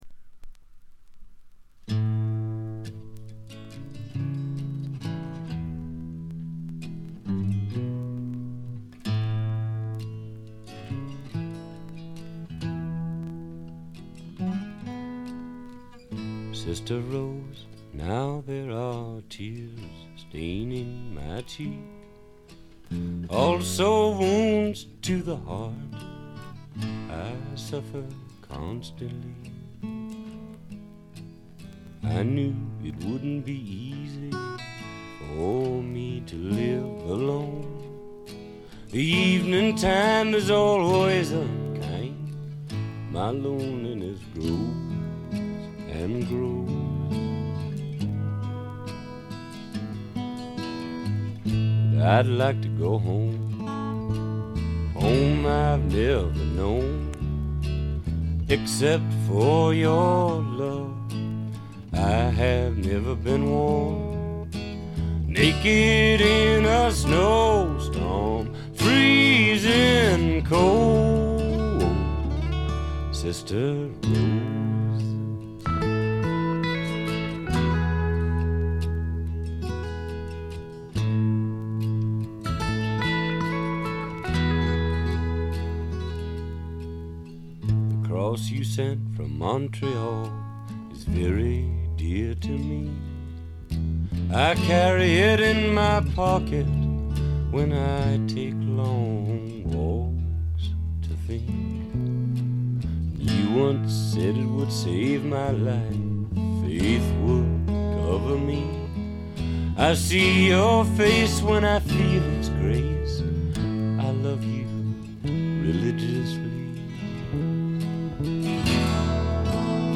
これ以外はわずかなチリプチ程度。
試聴曲は現品からの取り込み音源です。